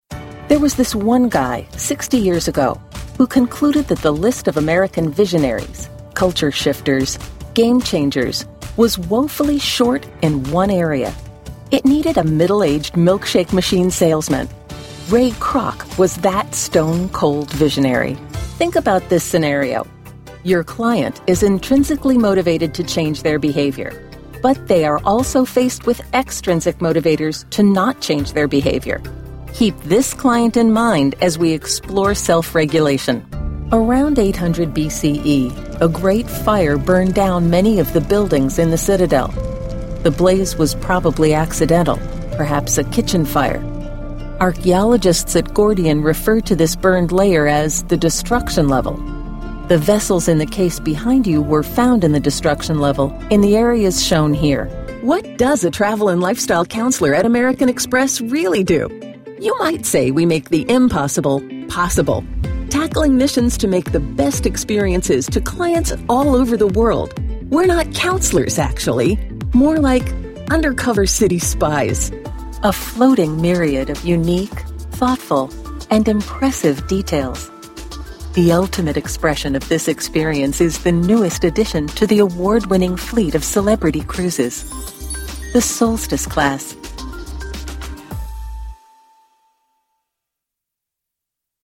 Clear, unaccented North American English voiceover.
Voice acting is conversational and natural, putting listeners at their ease while keeping their interest.
Sprechprobe: Industrie (Muttersprache):
confident, informative, knowledgeable, real sounding, conversational